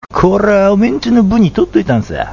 医療人のための群馬弁講座；慣用句